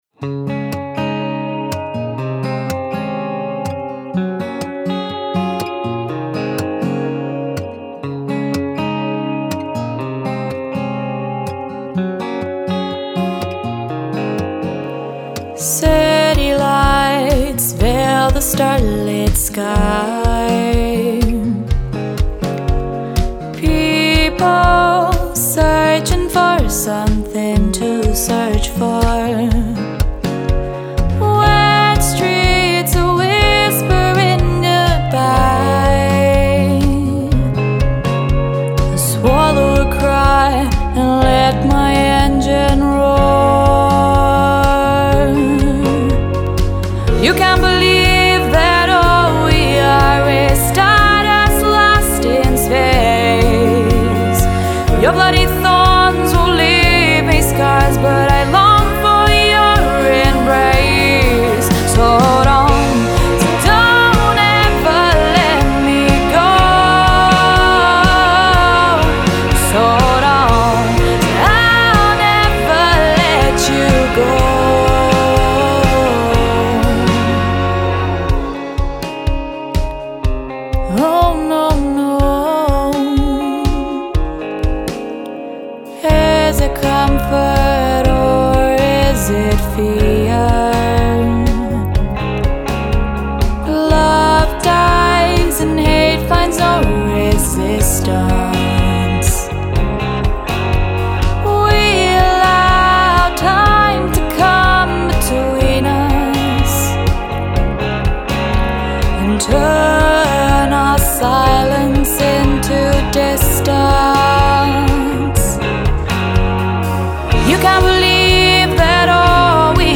along with a String Quartet
background vocals